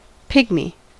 pygmy: Wikimedia Commons US English Pronunciations
En-us-pygmy.WAV